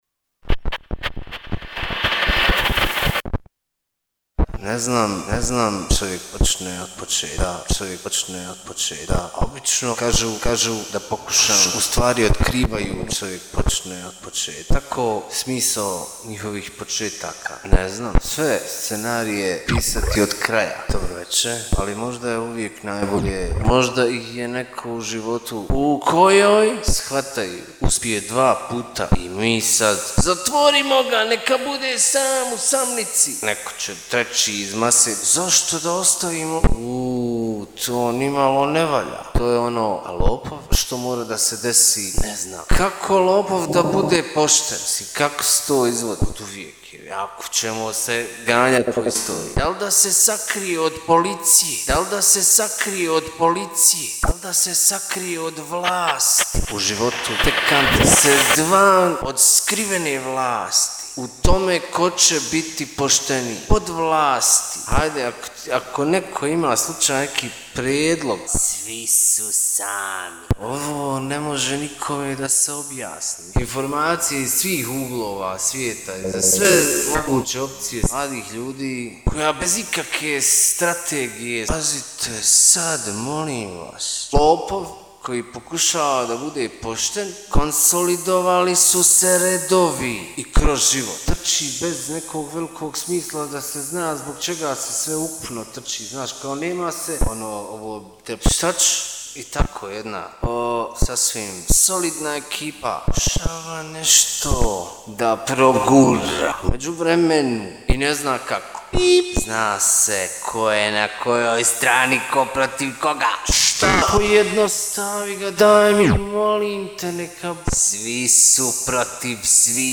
Tri priče ispričane jednim glasom. Kratkim rečenicama - pri kojim je u režiji svaki rez estetski naznačen zvukom "uzemljenja" - otvara se put u tri toka misli. Svaka misao je dekonstrukcijom odvojena od svog konteksta i predstavlja ekskurs unutar jednog diskursa.